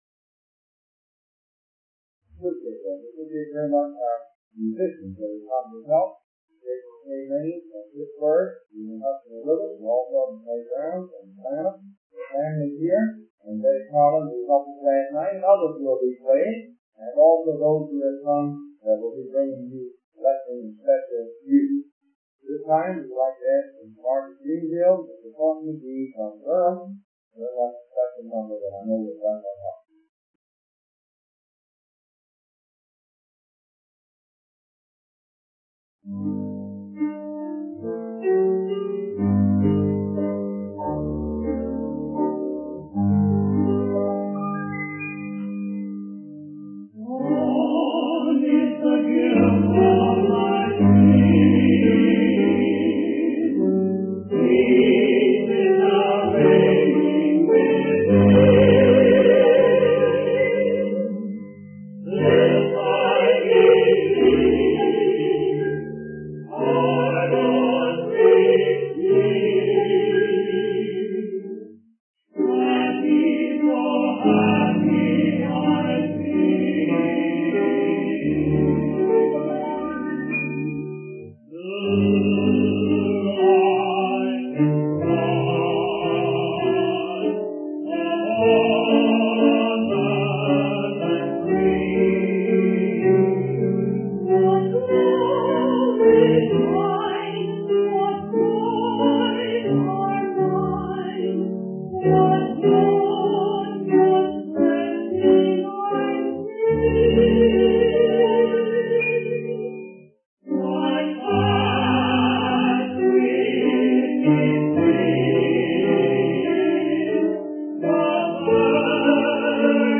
Bristol Conference 1964
The sermon transcript provided is not coherent and does not contain any clear message or content.